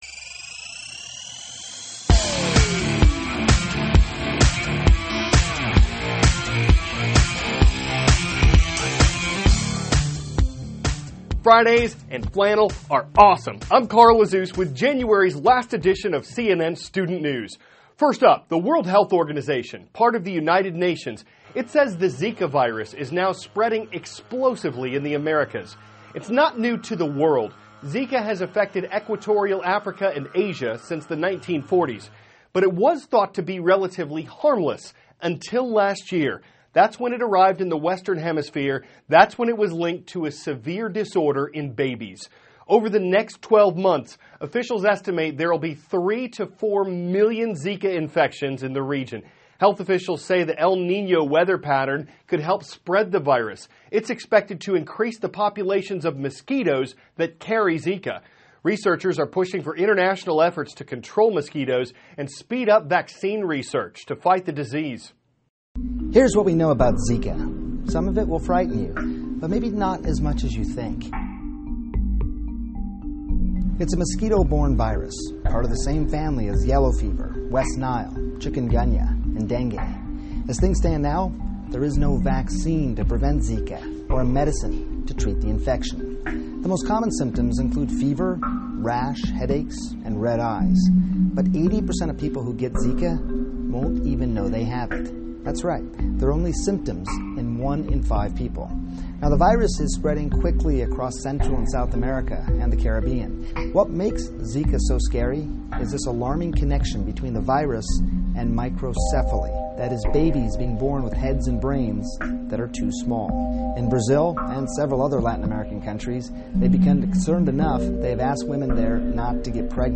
(CNN Student News) -- January 29, 2015 Experts Track "Explosively" Spreading Virus in the Americas; All Eyes on Iowa Ahead of State`s Upcoming Caucuses THIS IS A RUSH TRANSCRIPT.